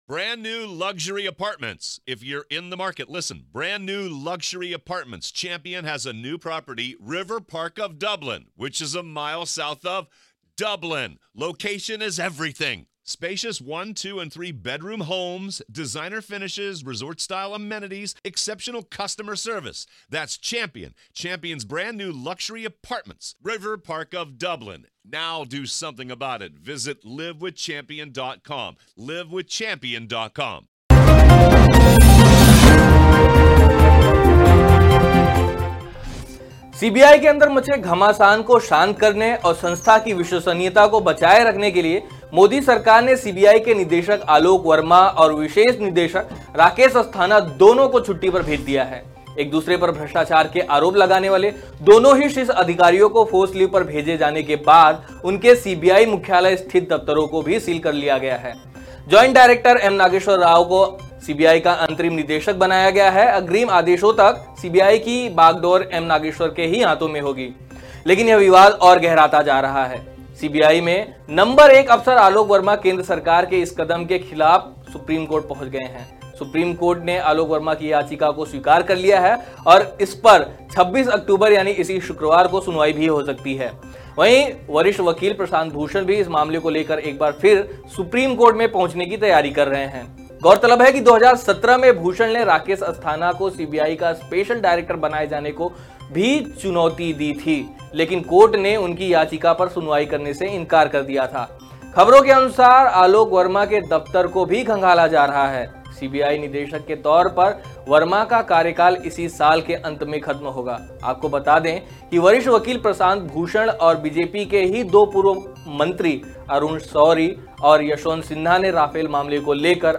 न्यूज़ रिपोर्ट - News Report Hindi / केंद्र सरकार के फैसले के खिलाफ सीबीआई चीफ़ सुप्रीम कोर्ट पहुंचे, मोदी सरकार की बढ़ी मुश्किलें